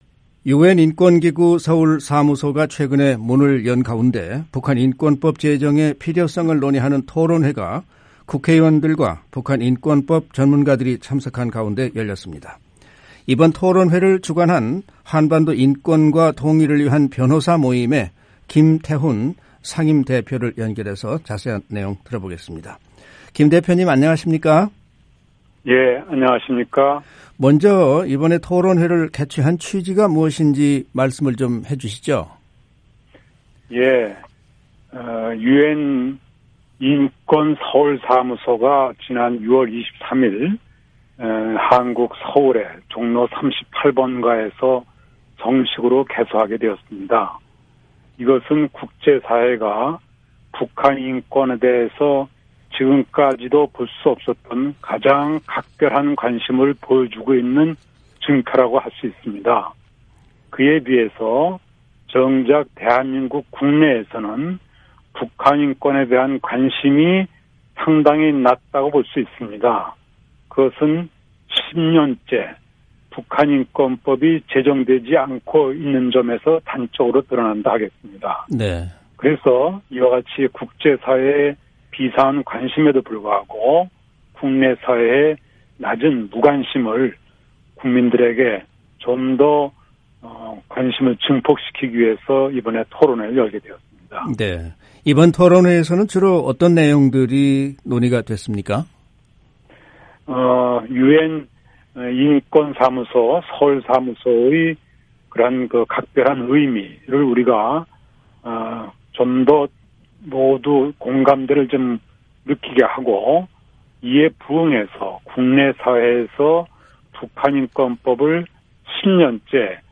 [인터뷰: